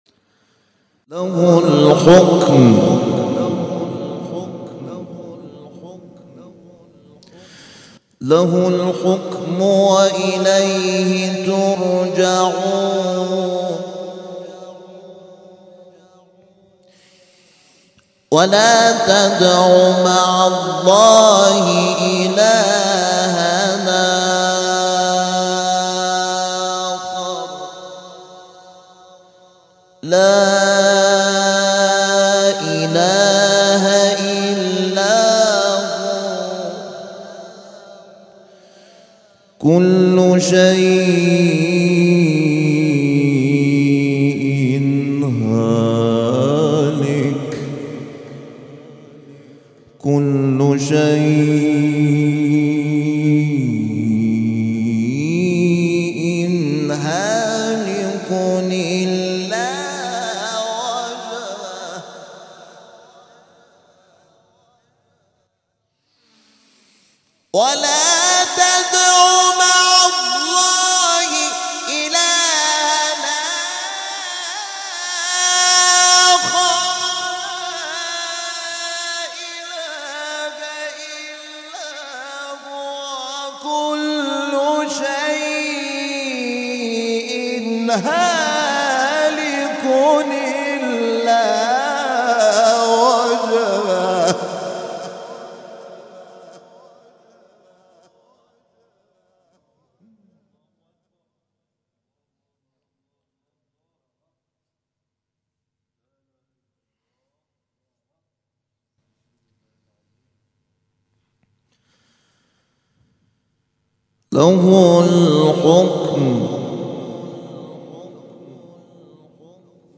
کرسی تلاوت